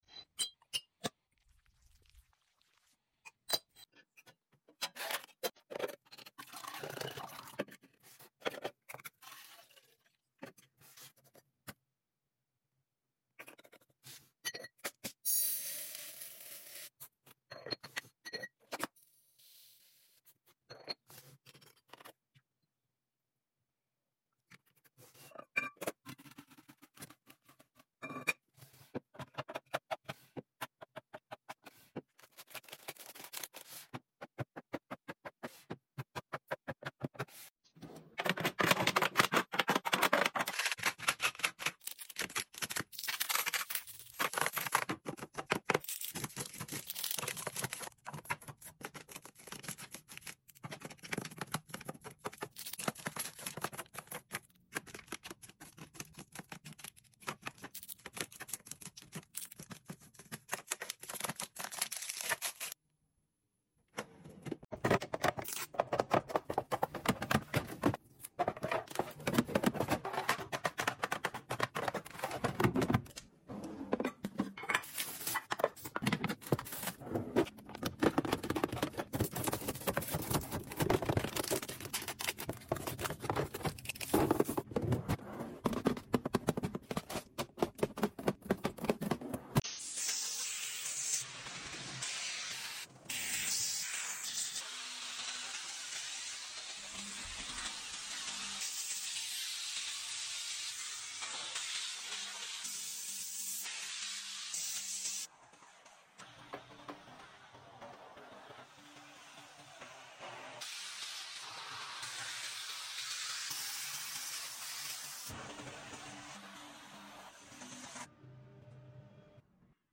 Random house restocking, cleaning and sound effects free download
Random house restocking, cleaning and organizing asmr🫧🧽🧼🧹.